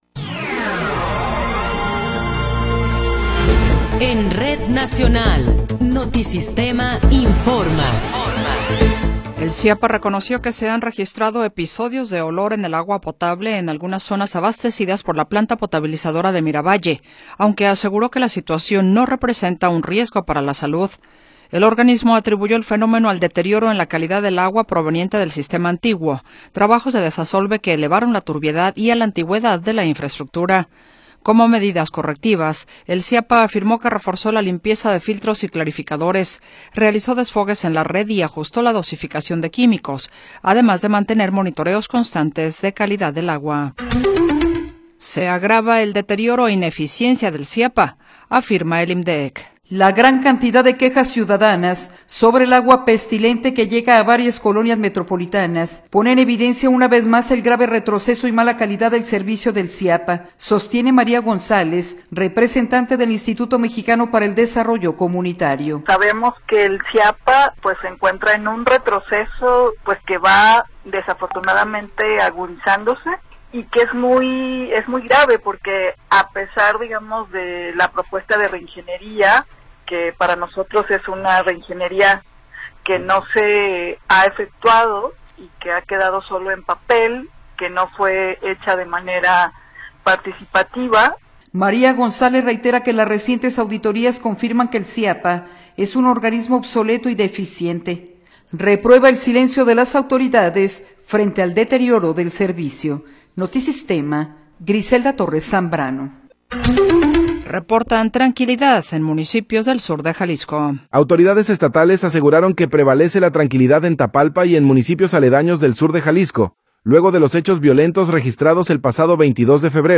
Noticiero 17 hrs. – 4 de Marzo de 2026
Resumen informativo Notisistema, la mejor y más completa información cada hora en la hora.